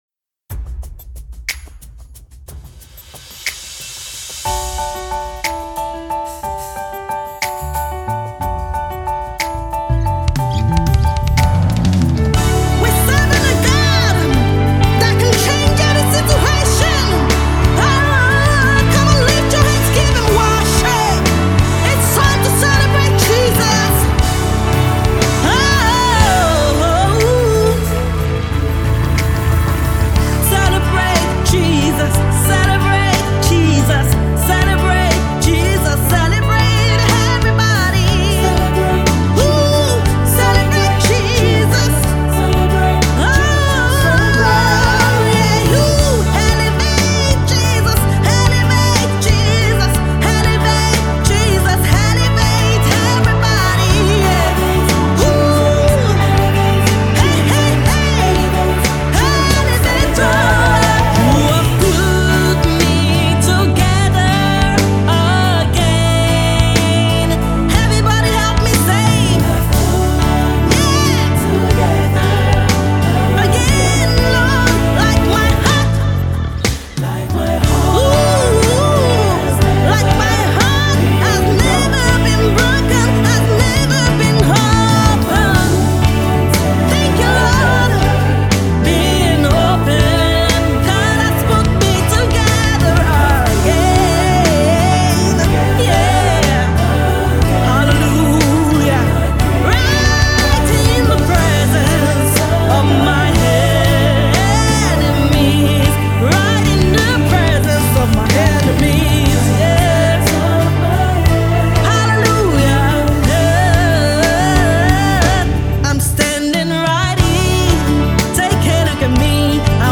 Nigerian-British Christian Gospel Singer and composer